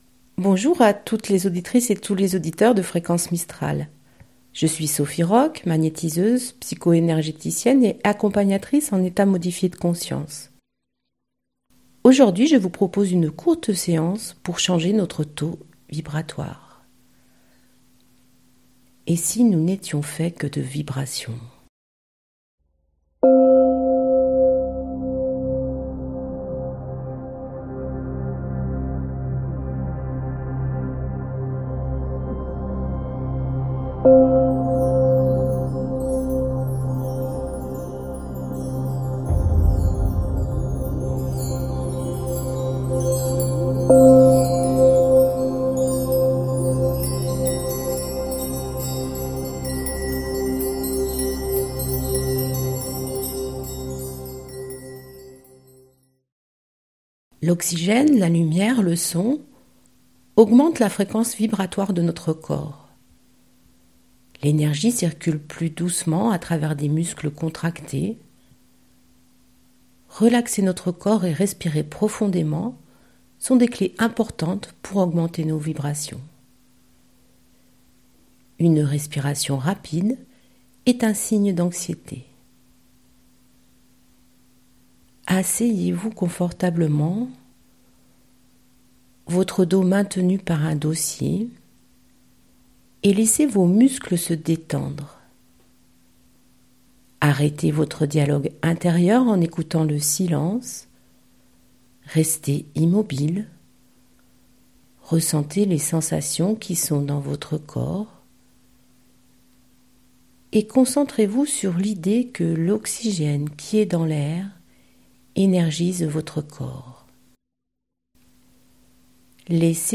Sur les ondes de fréquence mistral, je vous propose l'émission " En chemin vers soi ", deux fois par mois (second et quatrième mardi du mois) en direct, afin d'expérimenter des séances très simples : de respiration, de méditation, de visualisation. Le but de ces séances est de vous ramener vers votre intériorité, de vous aider à retrouver votre centre pour mieux vous sentir dans votre vie de tous les jours.